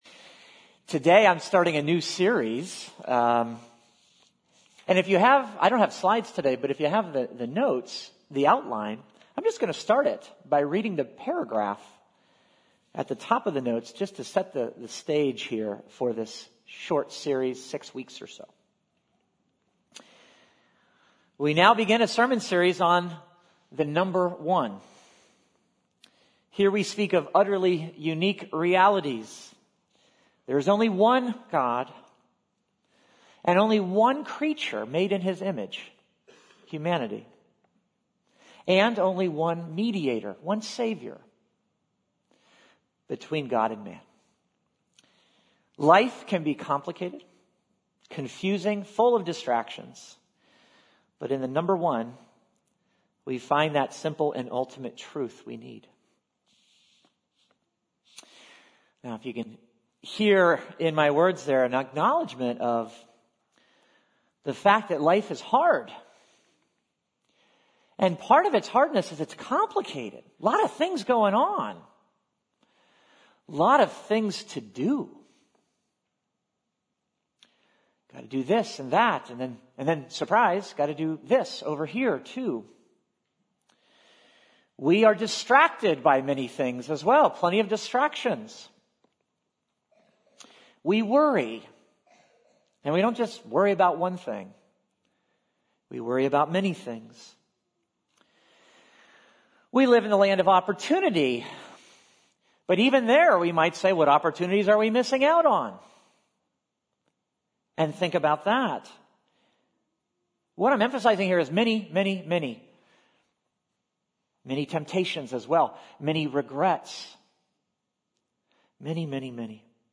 This is the RSS feed for Sunday sermons from New Life Presbyterian Church, Glenside, PA.